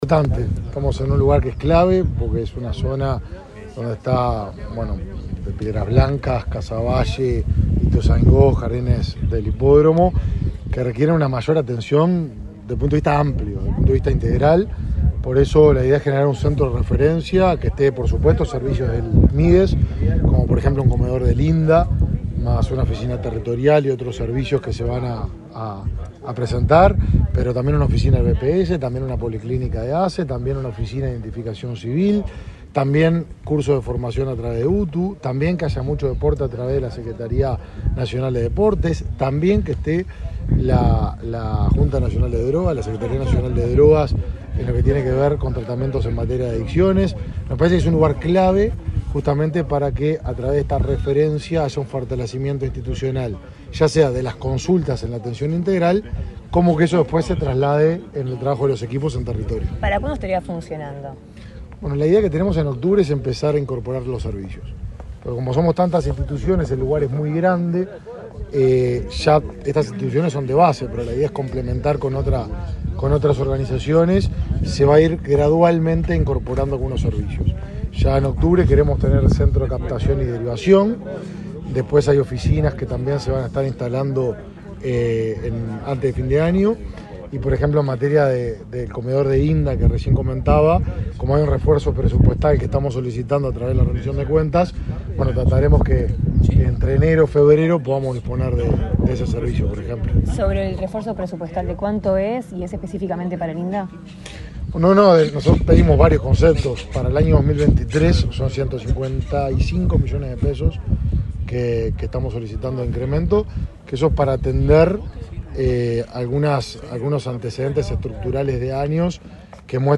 Declaraciones del ministro de Desarrollo Social, Martín Lema